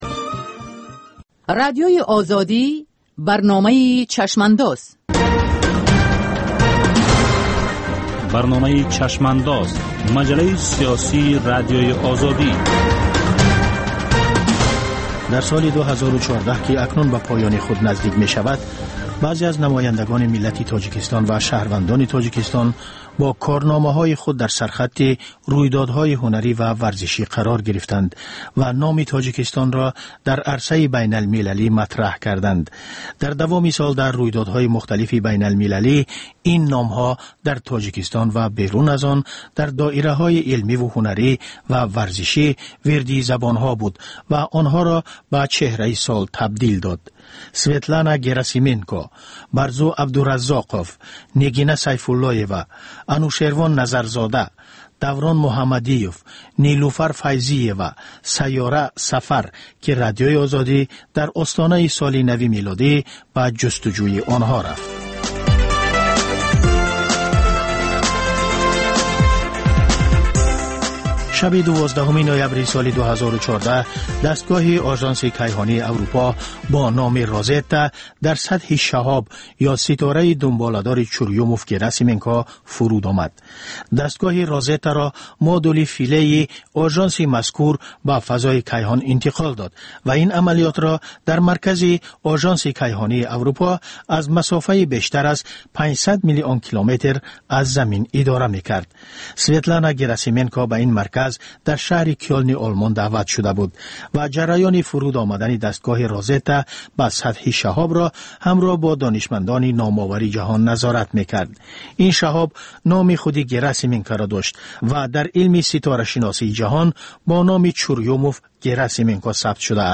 Сӯҳбати ошкоро бо чеҳраҳои саршинос ва мӯътабари Тоҷикистон дар мавзӯъҳои гуногун, аз ҷумла зиндагии хусусӣ.